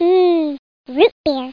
00754_Sound_rootbeer.mp3